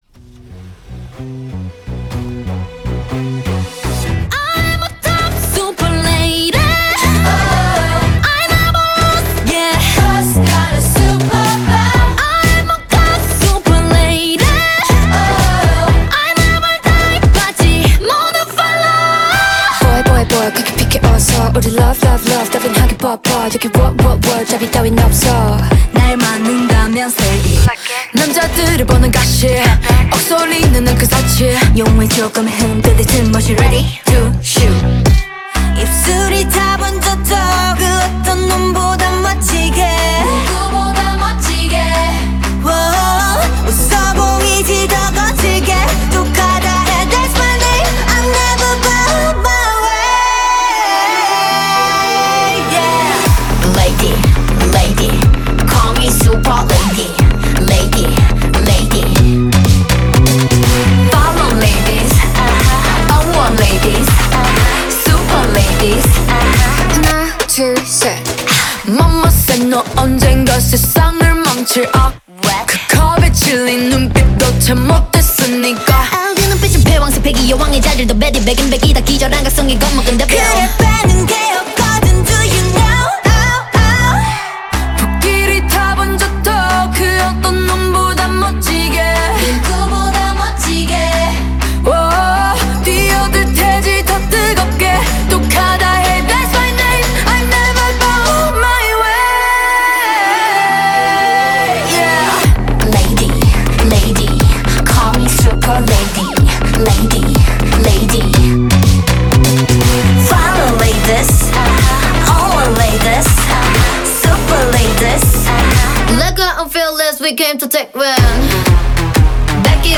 K-pop